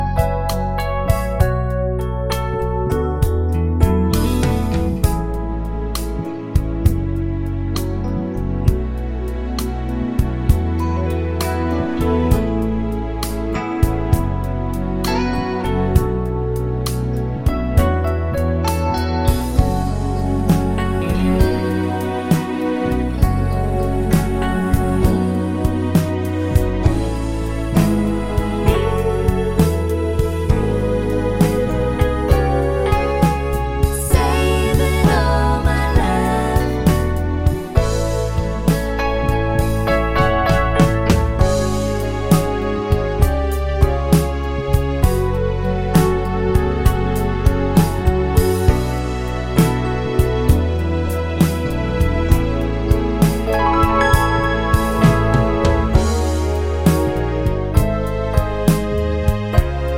no sax Pop (1980s) 4:00 Buy £1.50